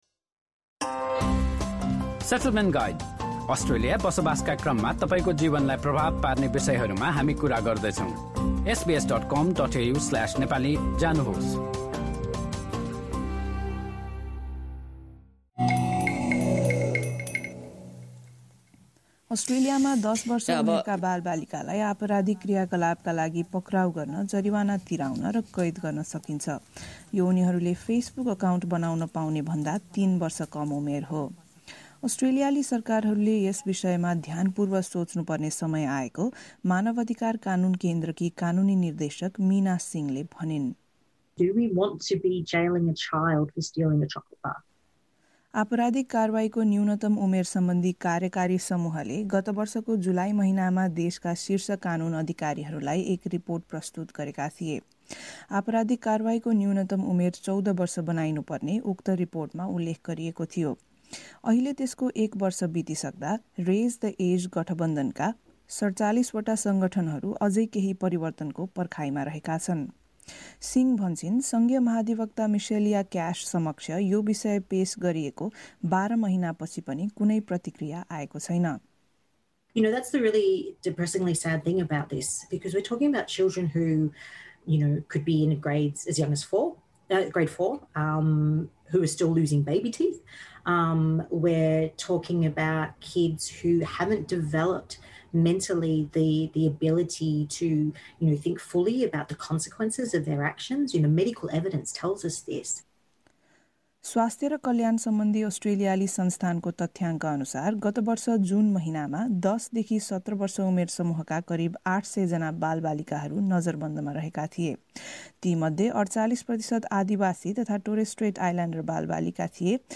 रिपोर्ट सुन्नुहोस्: null हाम्रा थप अडियो प्रस्तुतिहरू पोडकास्टका रूपमा यहाँबाट नि:शुल्क डाउनलोड गर्न सक्नुहुन्छ।